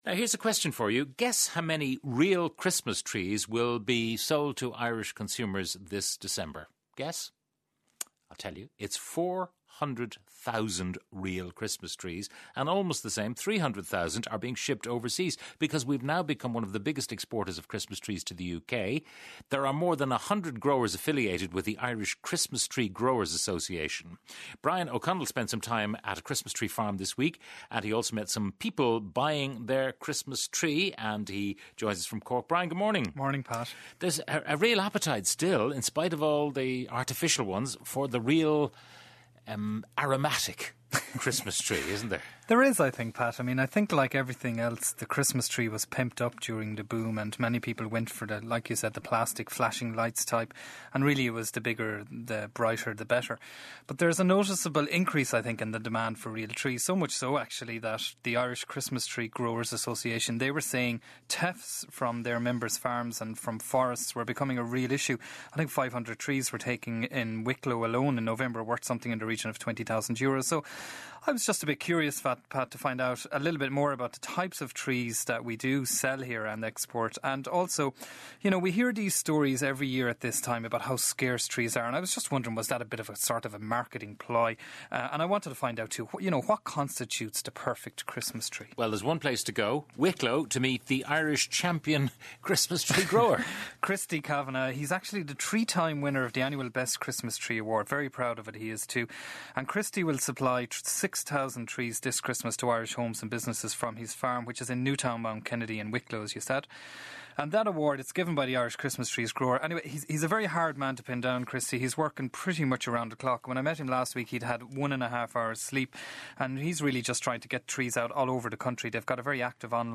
Report was broadcast on Today With Pat Kenny on RTE Radio 1, Thurs 19th Dec.